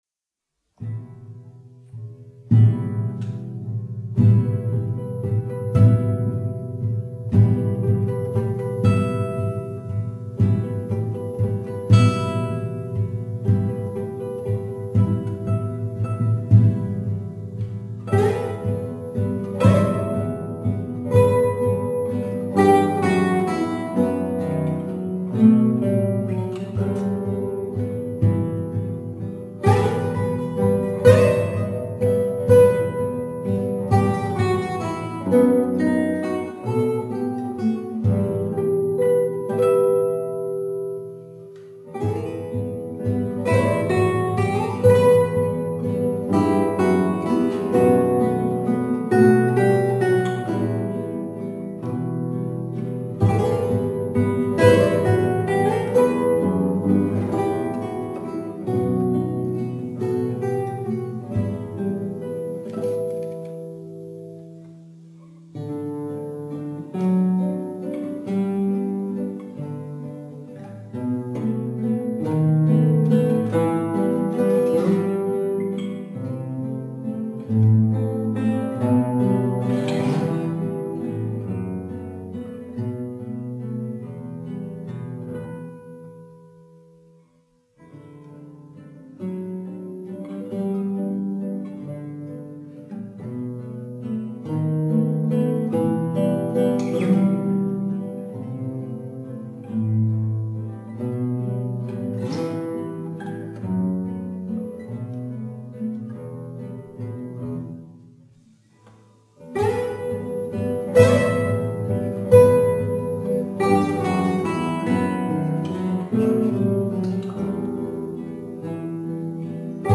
Vals 3.02